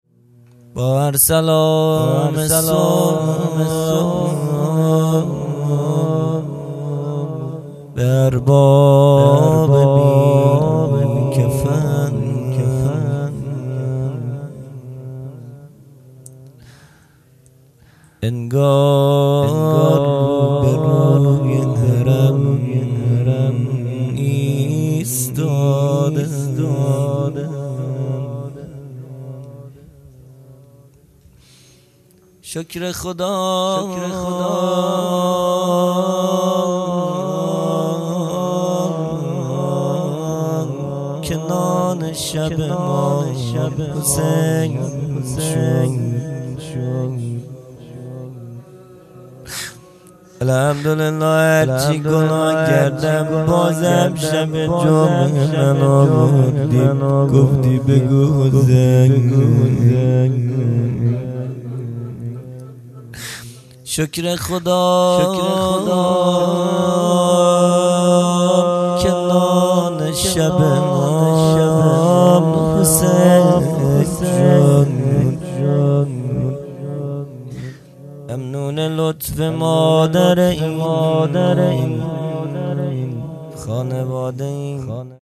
مناجات پایانی | با هر سلام به ارباب بی کفن
جلسه هفتگی | جلسه هفتگی هیئت به مناسبت شهادت امام صادق(ع) | پنجشنبه29خرداد99